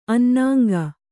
♪ annāŋga